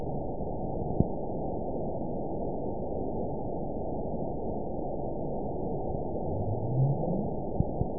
event 922526 date 01/25/25 time 20:35:43 GMT (10 months, 1 week ago) score 9.40 location TSS-AB04 detected by nrw target species NRW annotations +NRW Spectrogram: Frequency (kHz) vs. Time (s) audio not available .wav